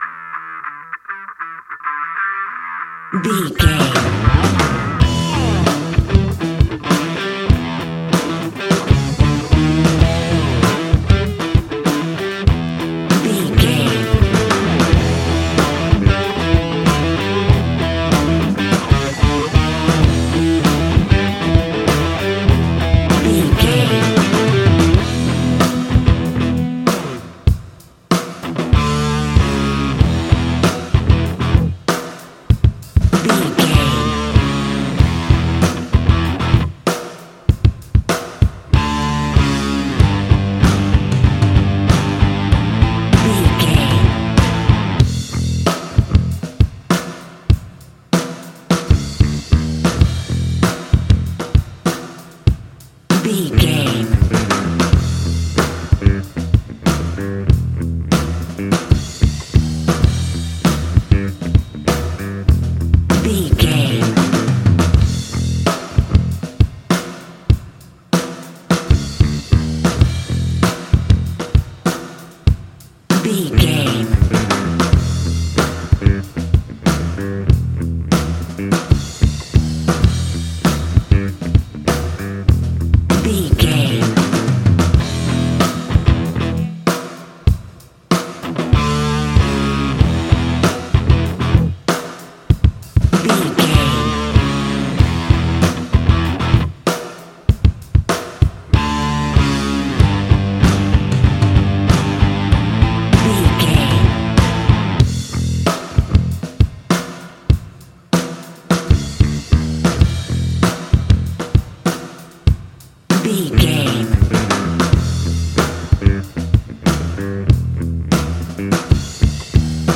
Ionian/Major
E♭
hard rock
heavy rock
distortion
instrumentals